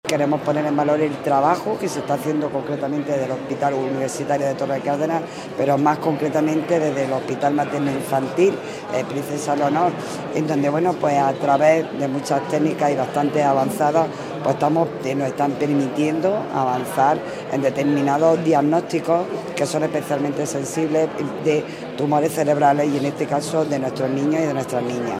Alcaldesa, delegada del Gobierno y vicepresidente de la Diputación inauguran este encuentro que, bajo el lema ‘Nuestros niños, nuestros pacientes, nuestra razón de ser’, se celebra del 19 al 21 de febrero
ARANZAZU-MARTIN-DELEGADA-GOBIERNO-CONGRESO-NEUROCIRUGIA-PEDIATRICA.mp3